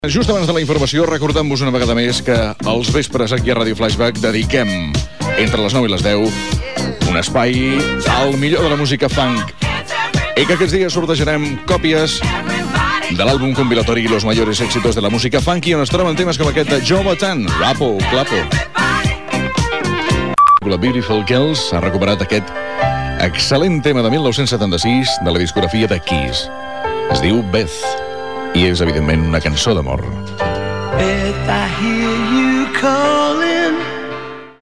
Radiofórmula musical: sorteig d'un disc i presentació d'un tema musical.
Musical
FM